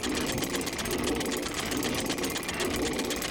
Golf_Windmill_Loop.ogg